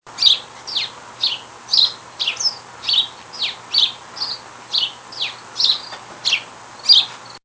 house sparrow
A second recording of the House Sparrow - this one's more of a "song".